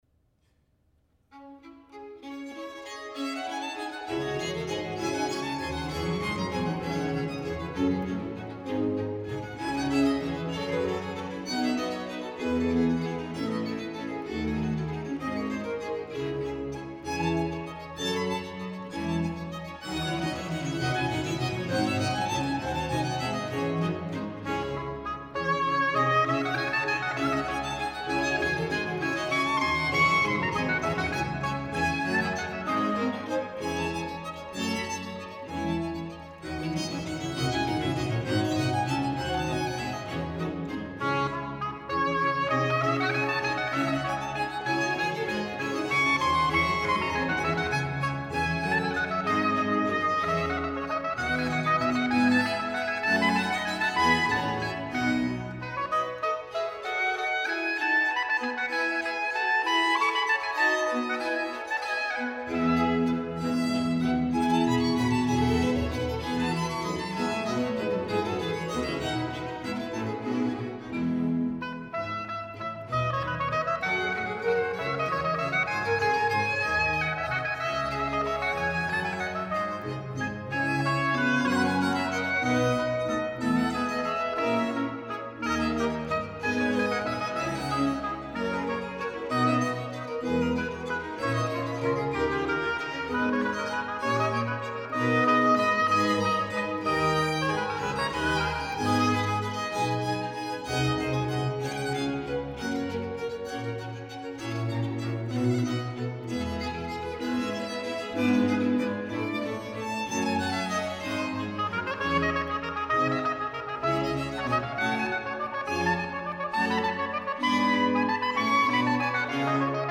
Concerto d-Moll für Oboe, Streicher und Basso continuo